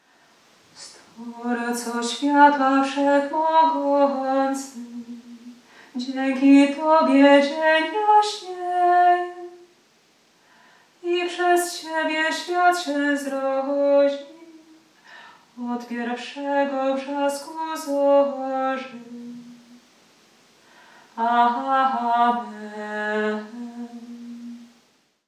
Hymn_8sylab_1_Stworco-swiatla-kjmqm03s.mp3